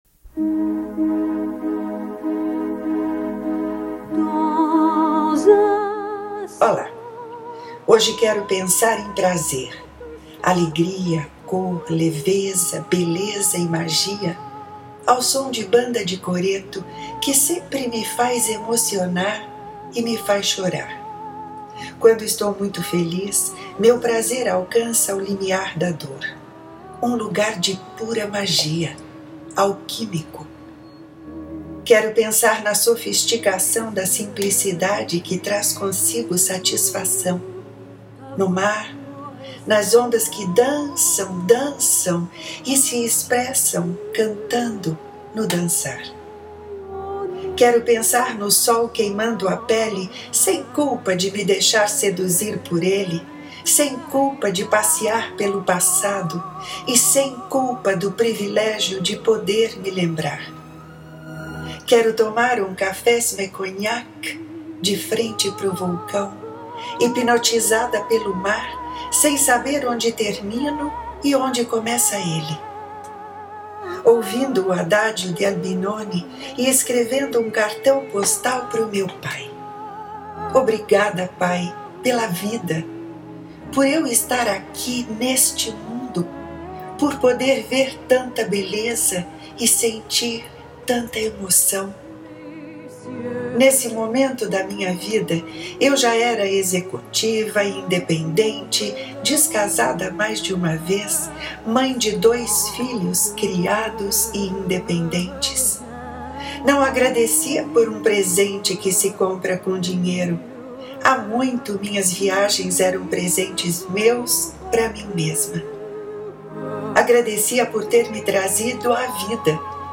Ouça “De Santorini” na voz e sonorizado pela autora